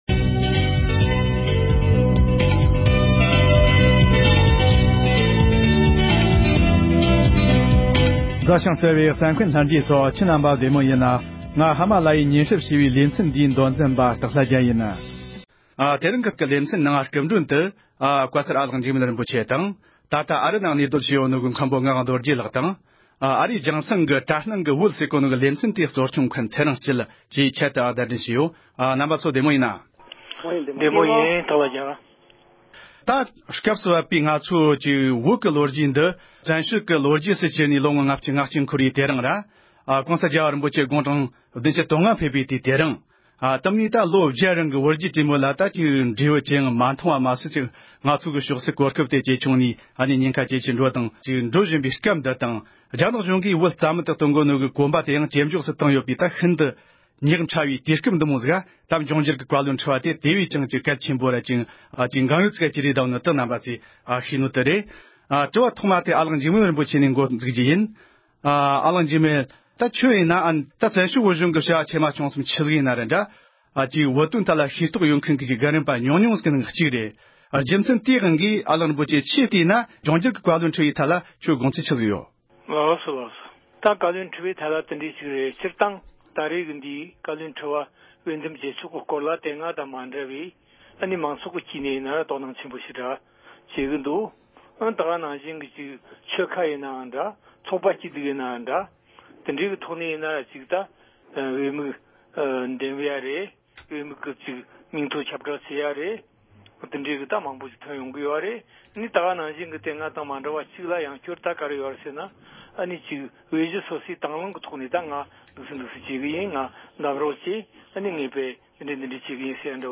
བཙན་བྱོལ་བོད་མིའི་འབྱུང་འགྱུར་བཀའ་བློན་ཁྲི་པའི་ཐད་བགྲོ་གླེང༌།